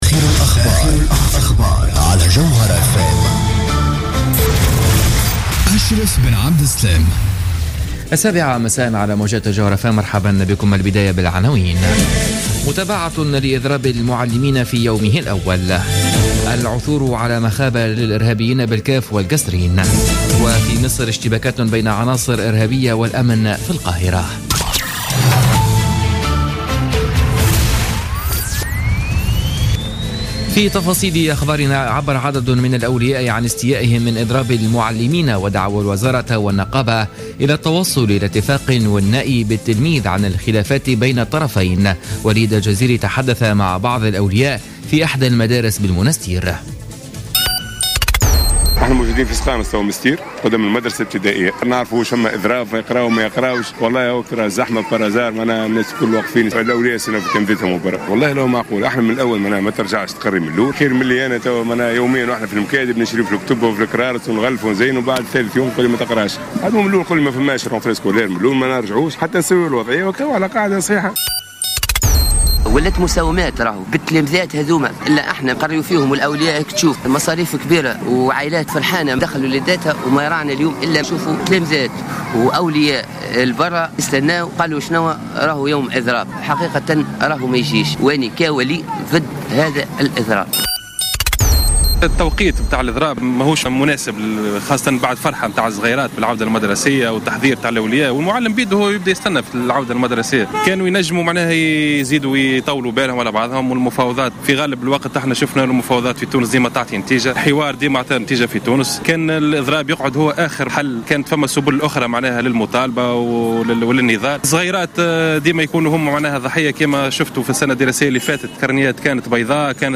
نشرة أخبار السابعة مساء ليوم الخميس 17 سبتمبر 2015